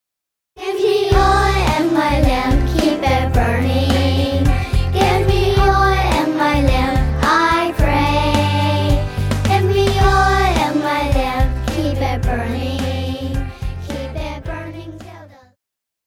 Christian
Pop chorus,Children Voice
Band
Hymn,POP,Christian Music
Voice with accompaniment